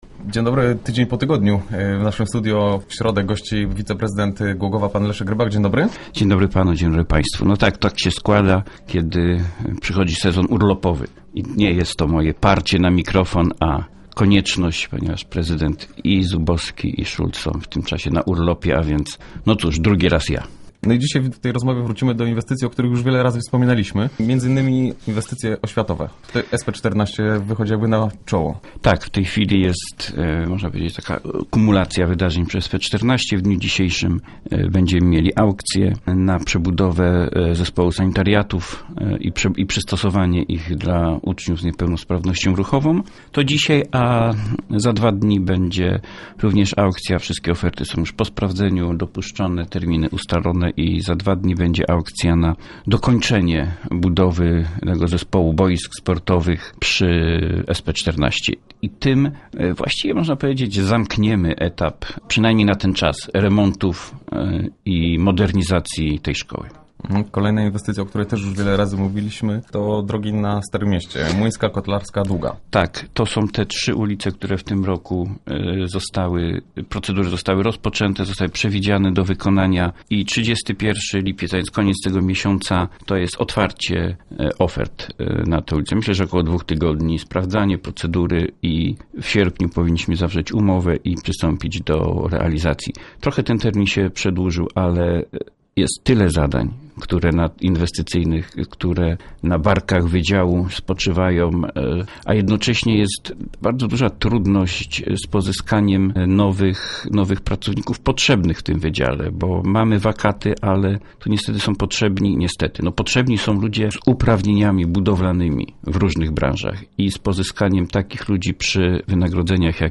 Start arrow Rozmowy Elki arrow Rybak: Rozstrzygamy kilka ważnych przetargów
W najbliższych dniach dowiemy się, kto wykona kilka inwestycji w Głogowie – remont łazienek i boisk w SP14, nowe nawierzchnie na kilku ulicach Starówki oraz odnowa lapidarium kościoła Łódź Chrystusowa. O szczegółach mówi Leszek Rybak, zastępca prezydenta miasta.